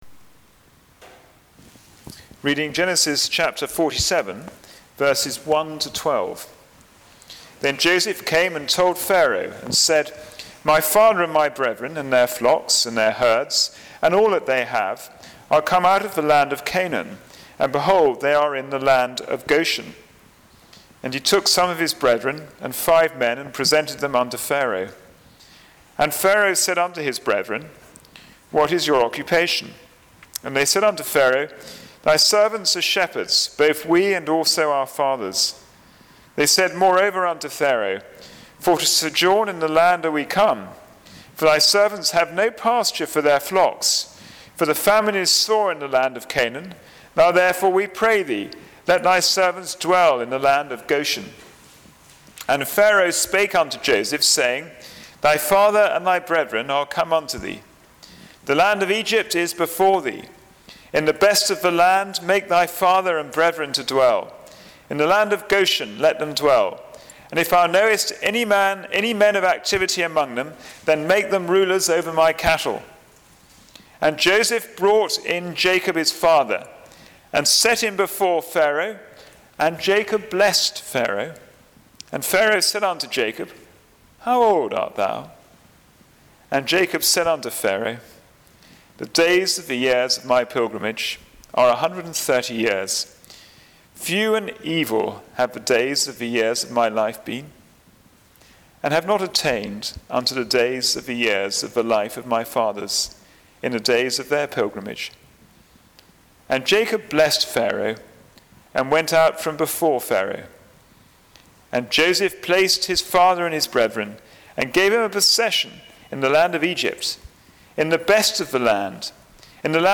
Passage: Genesis 47:1-12 Service Type: Sunday Morning Service « God’s Desire for Men to be Saved from Sin The Proclamation of the Gospel